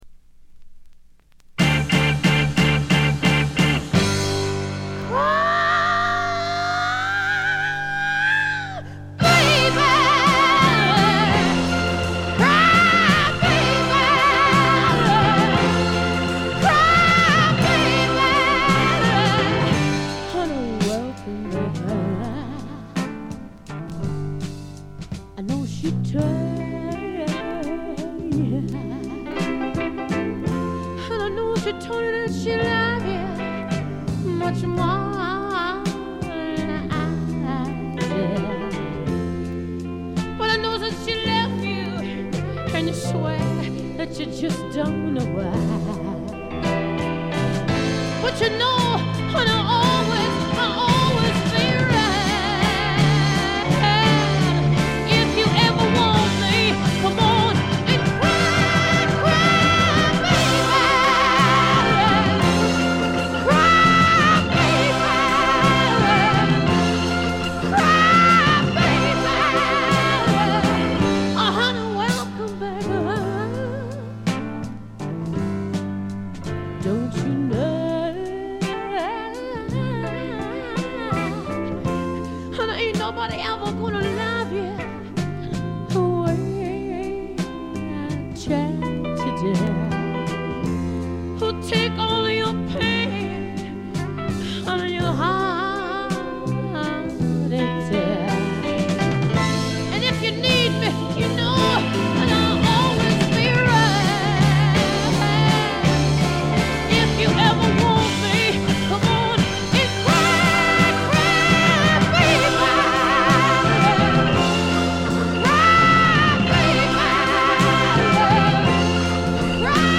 これ以外は細かなバックグラウンドノイズ、チリプチ少々。
試聴曲は現品からの取り込み音源です。